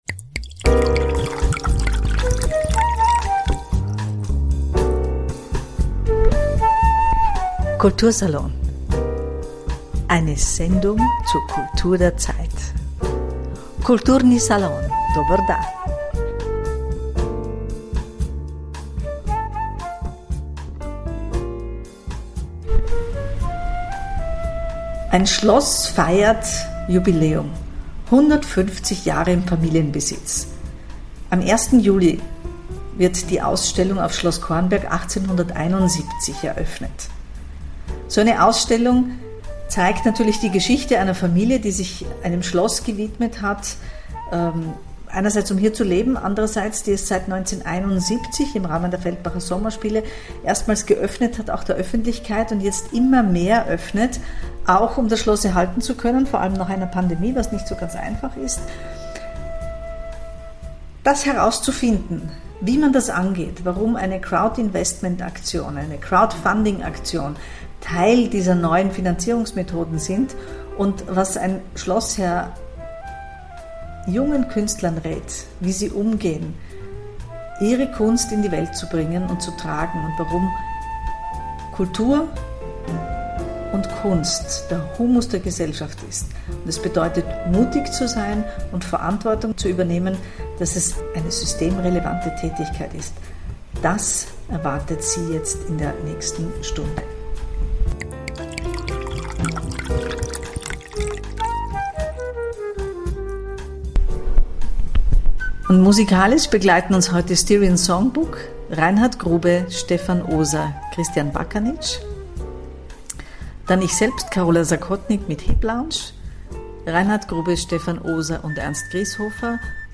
Entspannte Atmosphäre und kultivierte Gespräche - ja das geht.